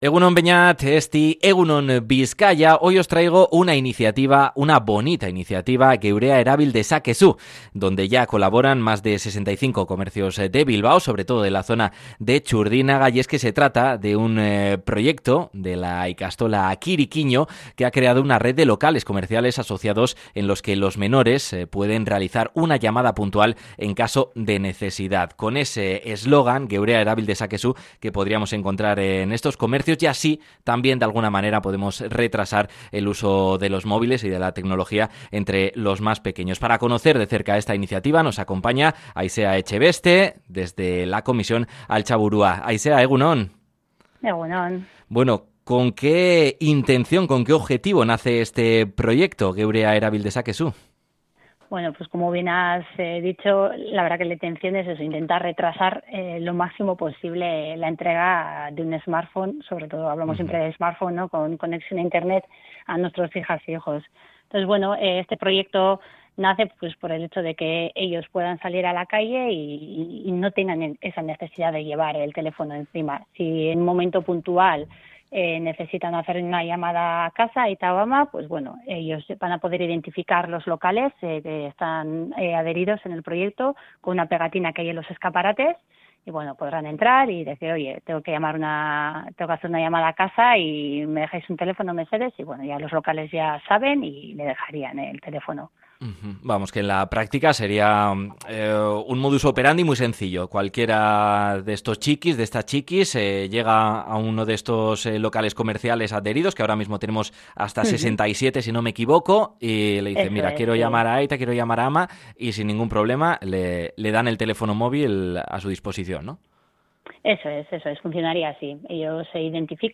Podcast Sociedad